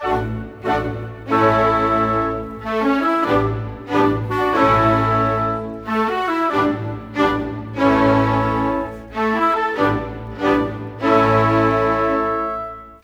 Rock-Pop 06.wav